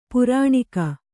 ♪ purāṇika